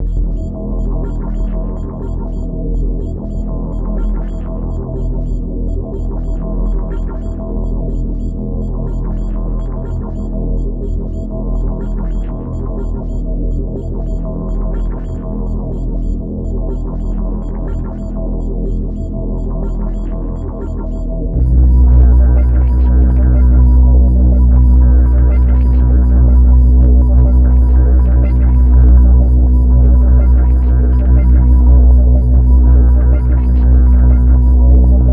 ambiant / alien_space